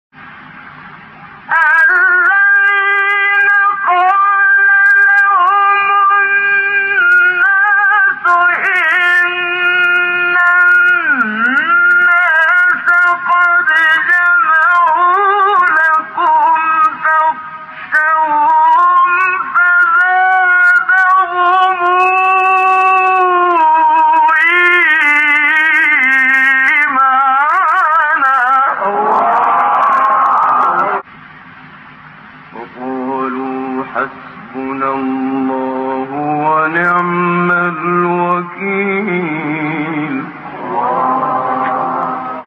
گروه فعالیت‌های قرآنی: فرازهای صوتی دلنشین با صوت قاریان برجسته مصری ارائه می‌شود.